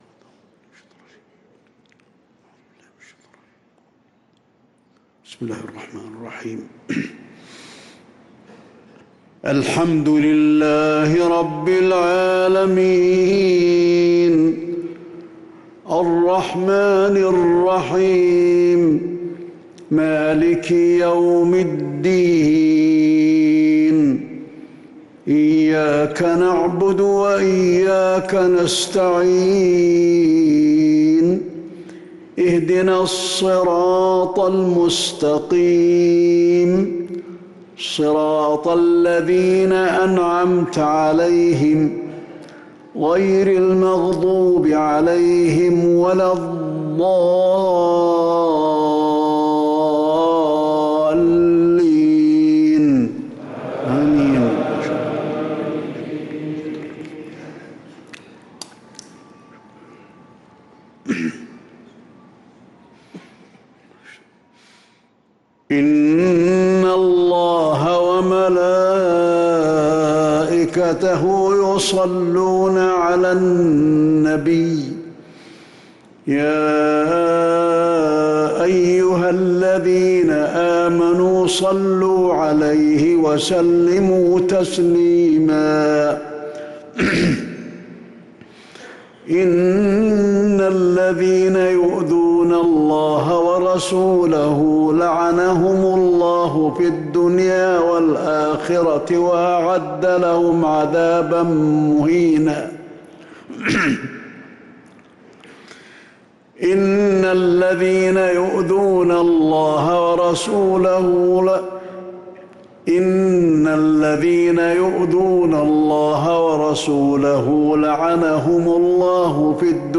صلاة العشاء للقارئ علي الحذيفي 13 رجب 1445 هـ